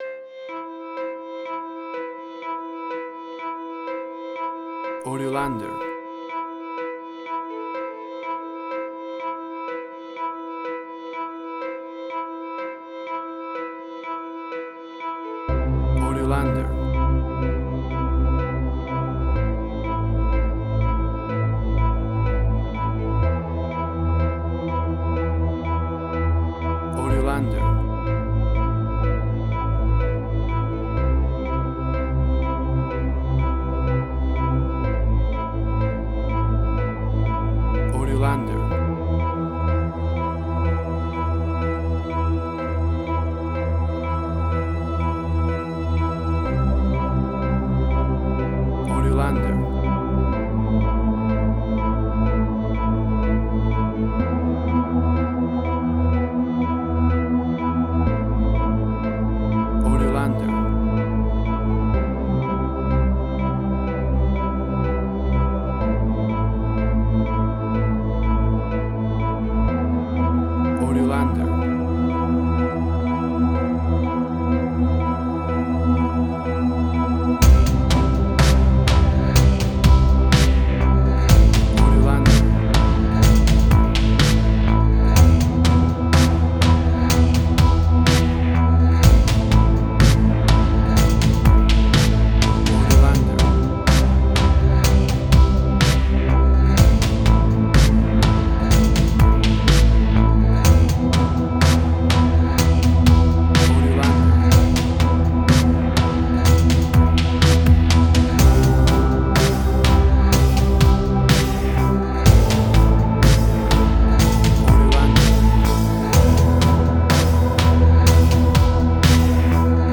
Future Retro Wave
New Wave
Tempo (BPM): 124